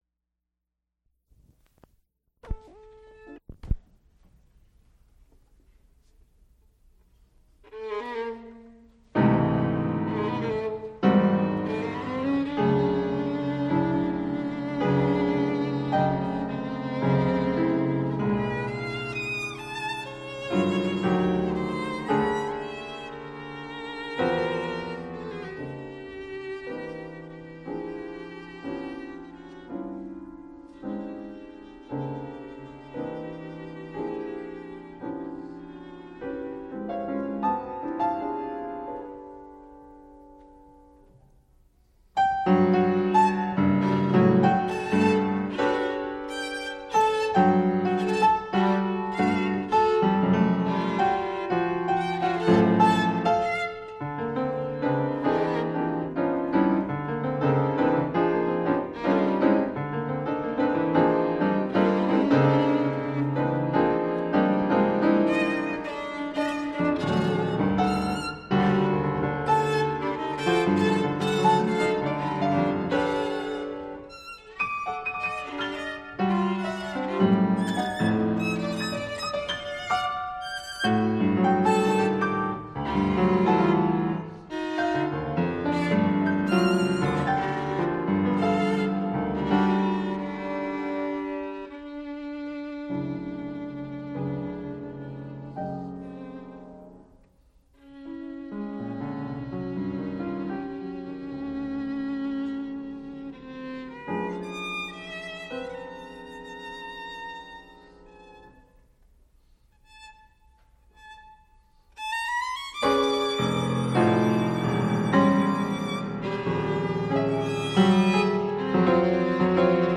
soprano
Instrumental ensembles Folk songs, Persian